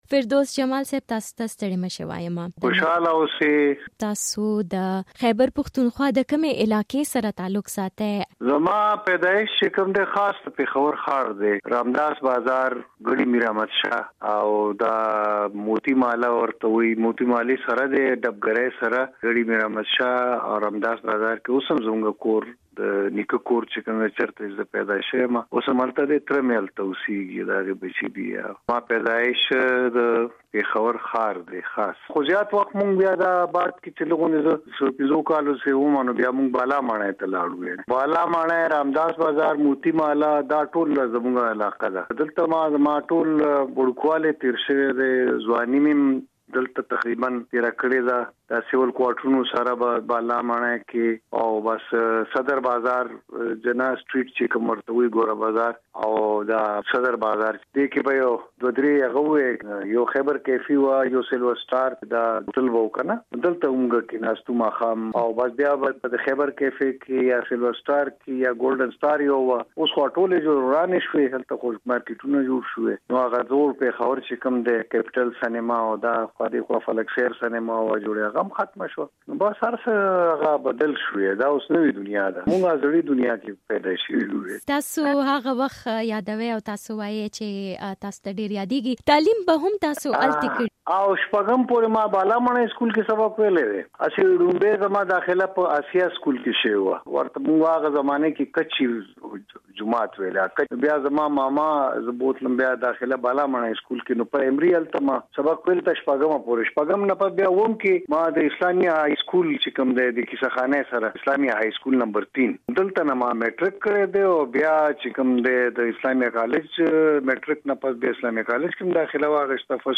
له فردوس جمال سره د مشال مرکه دلته واورئ